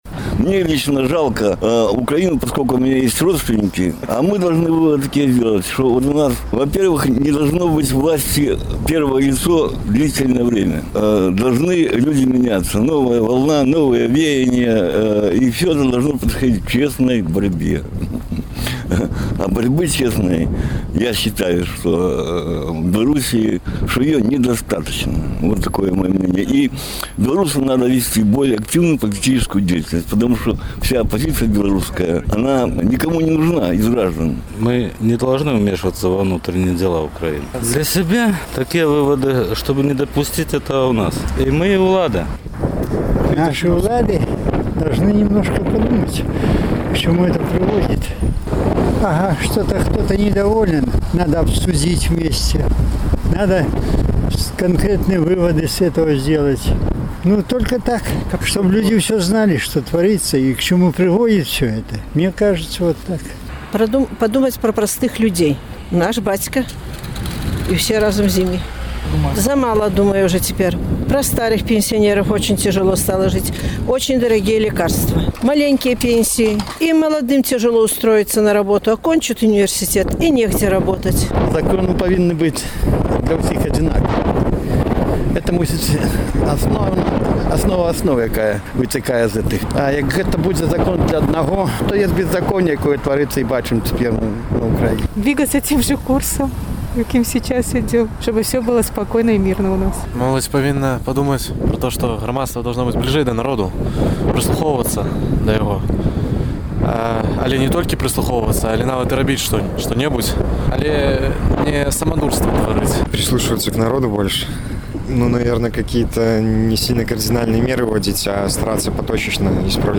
Якія высновы павінны зрабіць беларуская ўлада і грамадзтва з украінскіх падзей? Апытаньне ў Горадні